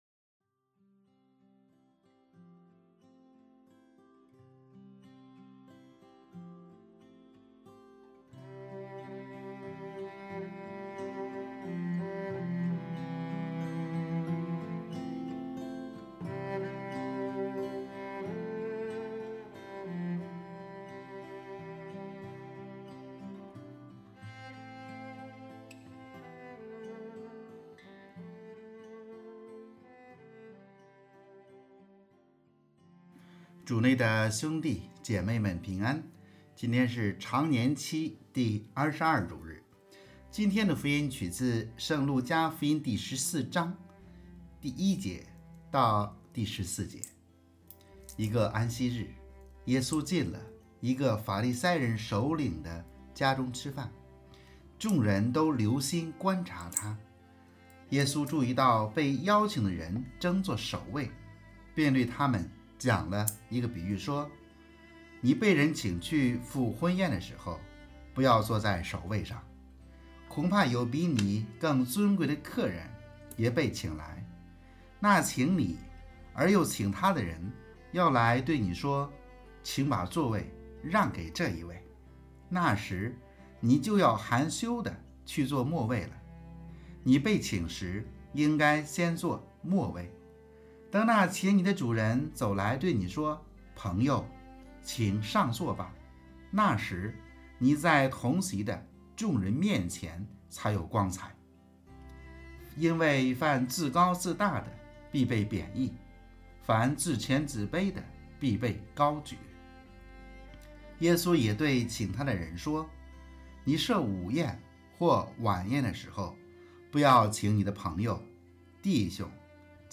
【主日证道】|餐桌尽显信仰真（丙-常年期第22主日）